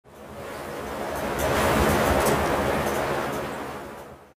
wind_gust2.mp3